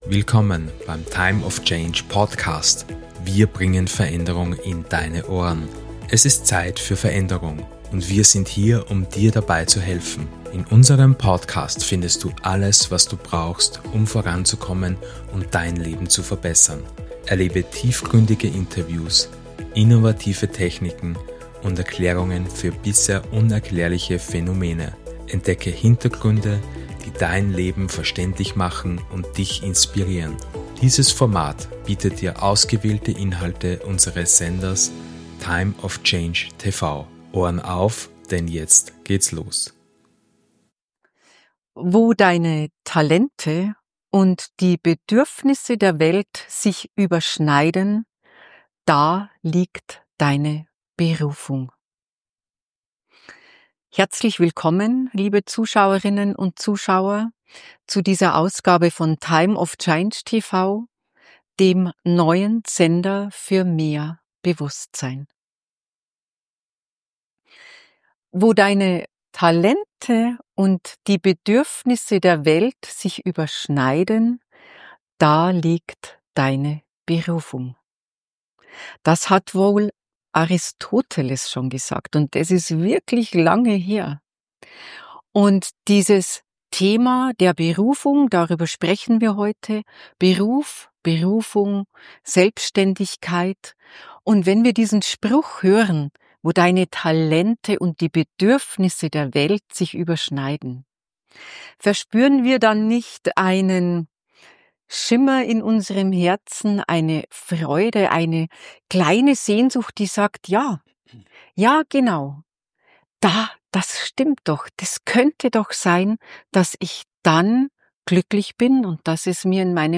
Dieses Gespräch geht weit über oberflächliche Karriereratschläge hinaus.